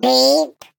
Sfx_tool_spypenguin_vo_horn_04.ogg